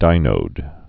(dīnōd)